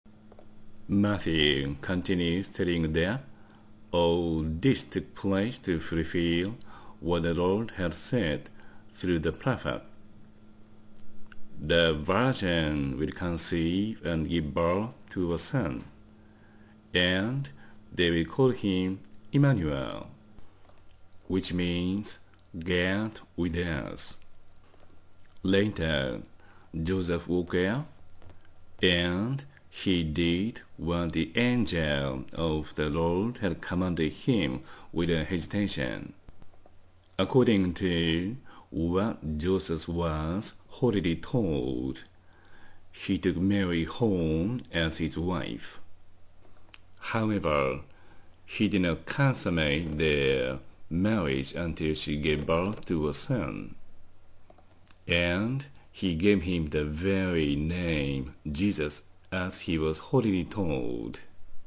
⇒ The birth of Jesus Christ Ⅱ（英語音声講義）